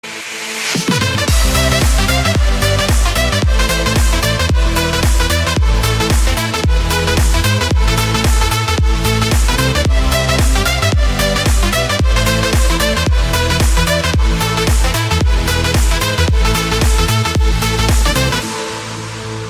Kategorie Remixy